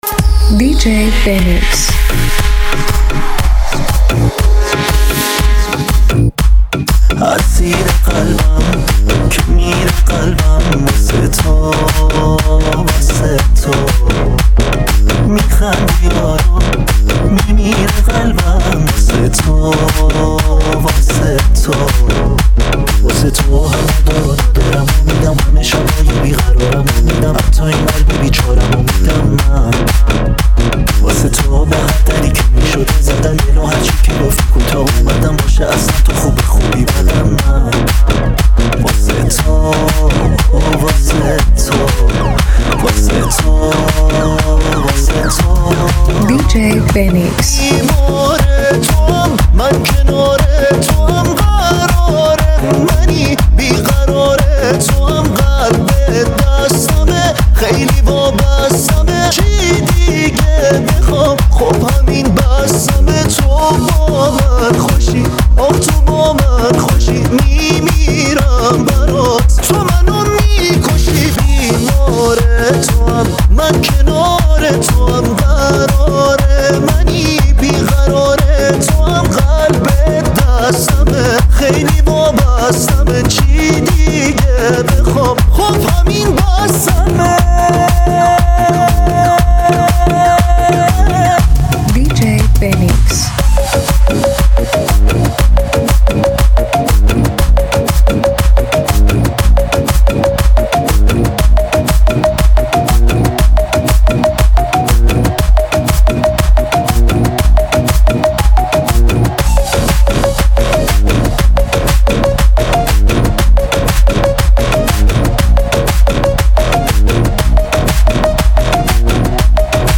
دانلود ریمیکس شاد و پرانرژی آهنگ